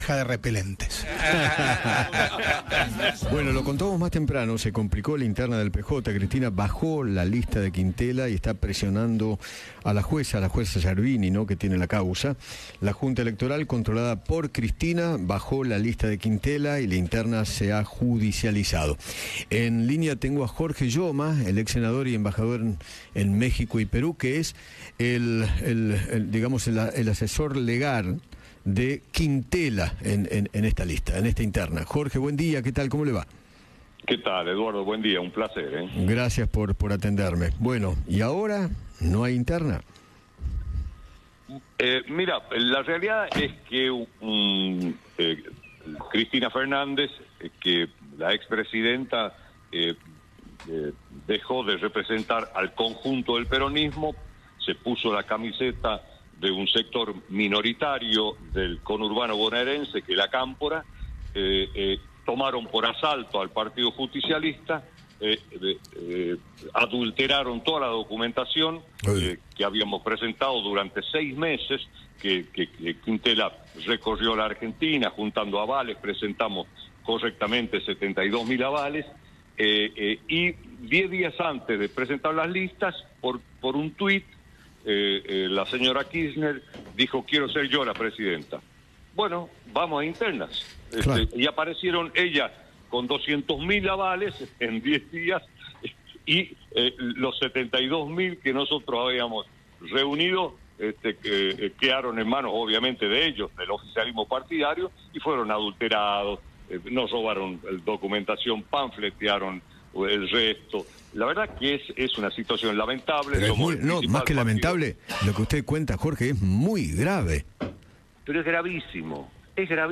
El ex senador Jorge Yoma conversó con Eduardo Feinmann sobre la interna del PJ y acusó al sector de la expresidenta de hacer fraude con los avales para impedir la participación de Ricardo Quintela.